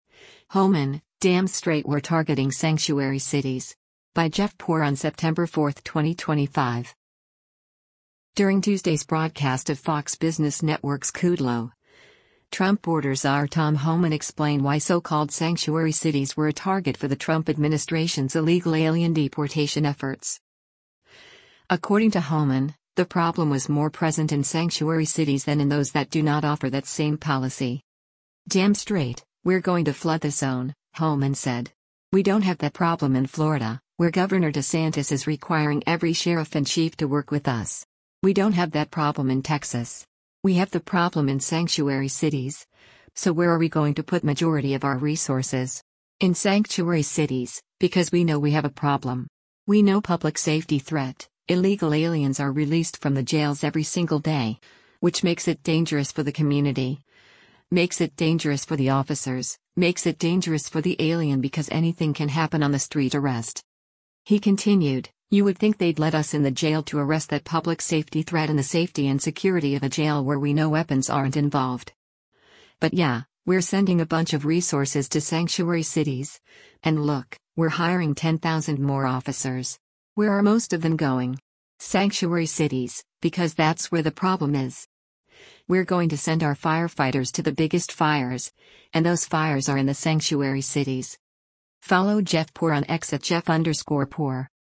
During Tuesday’s broadcast of Fox Business Network’s “Kudlow,” Trump border czar Tom Homan explained why so-called sanctuary cities were a target for the Trump administration’s illegal alien deportation efforts.